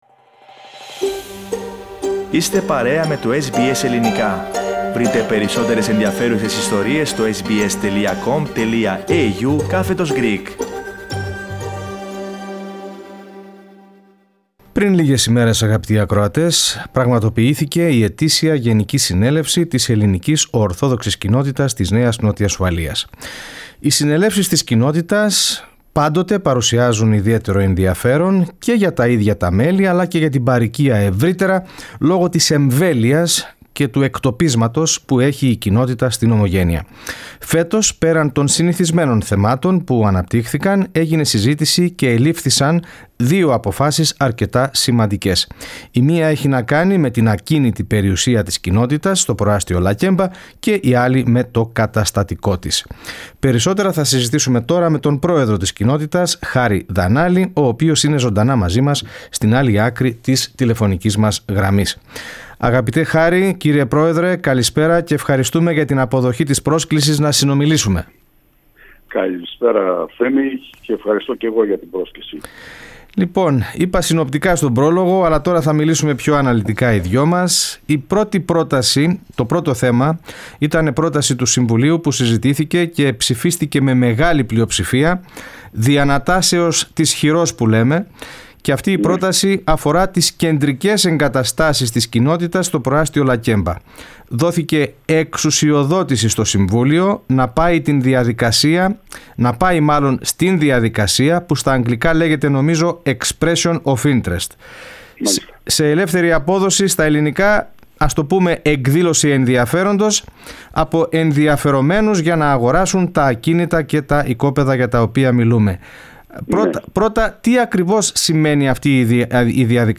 Press Play to hear the full interview in Greek.